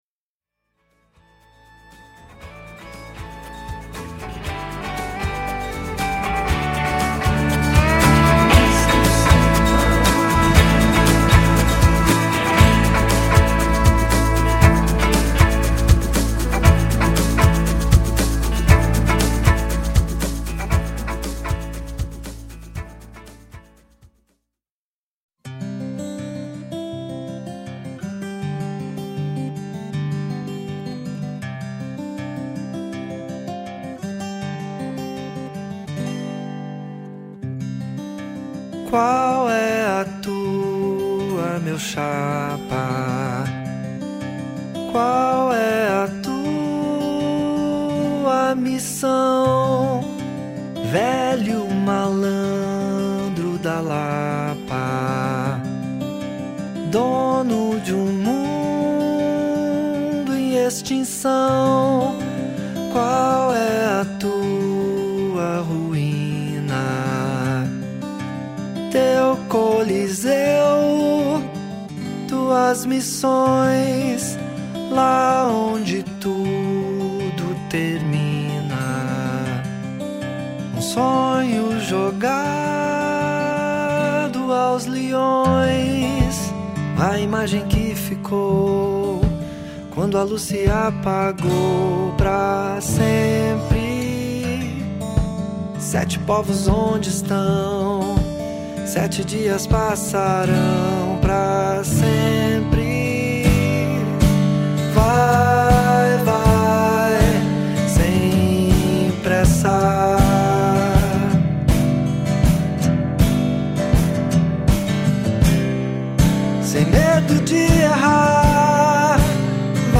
baixo fretless